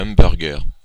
Ääntäminen
Ääntäminen France (Normandie): IPA: [am.bœʁ.ɡœʁ] Tuntematon aksentti: IPA: /ɑ̃.bœʁ.ɡœʁ/ IPA: /ɑ̃.buʁ.ɡɛʁ/ Haettu sana löytyi näillä lähdekielillä: ranska Käännöksiä ei löytynyt valitulle kohdekielelle.